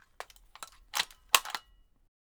Bullet In 3.wav